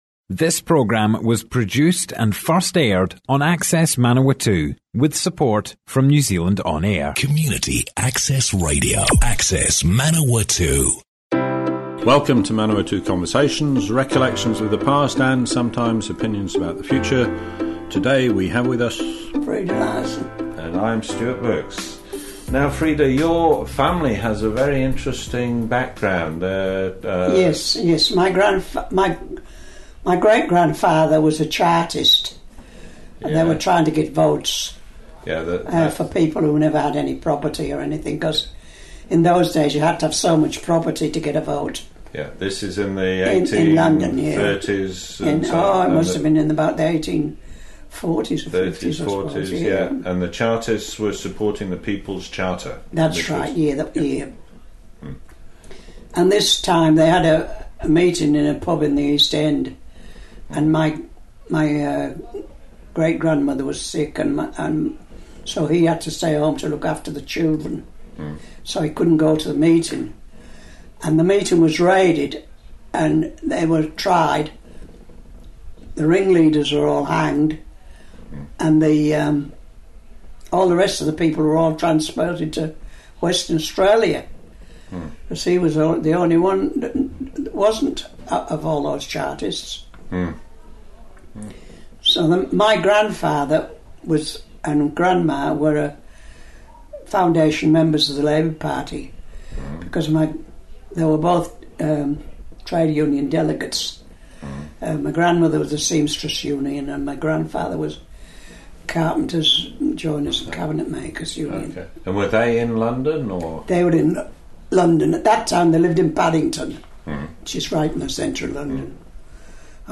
The interview was recorded for a series of talks played on Access Radio Manawatu, now called Manawatu People's Radio.
oral history